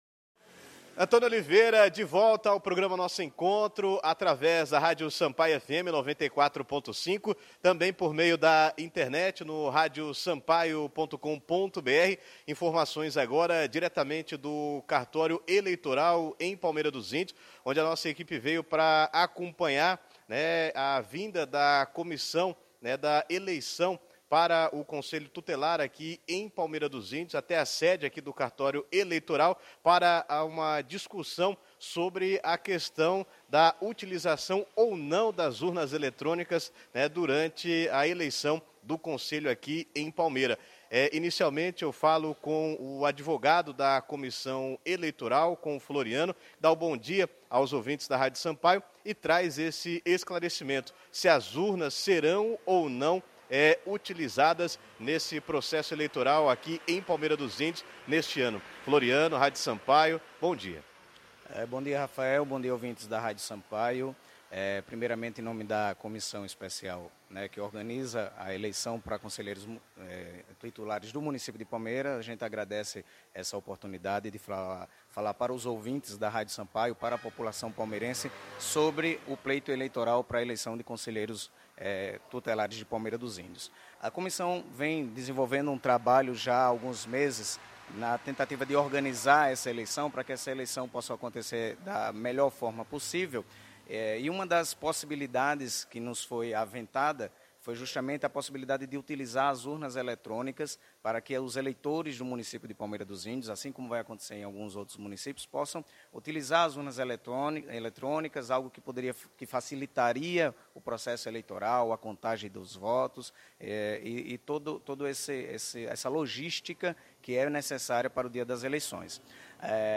entrevista-eleicao-conselho-tutelar.mp3